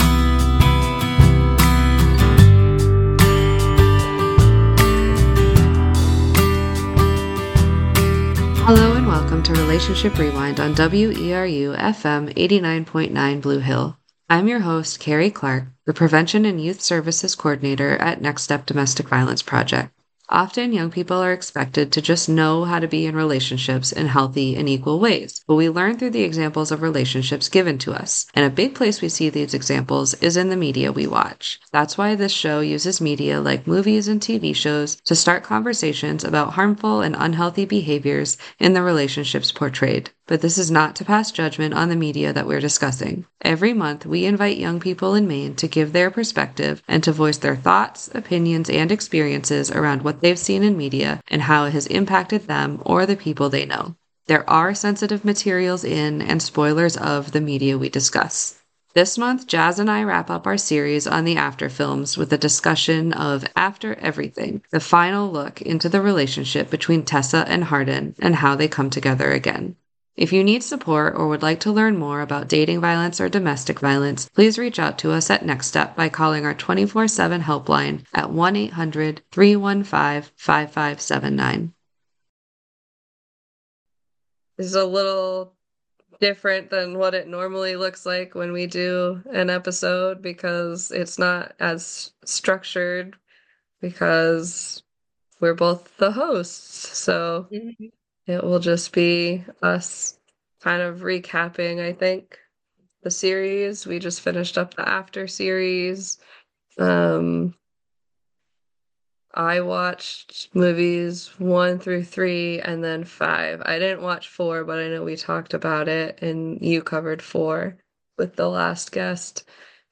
Theme Music for the show donated by local musicians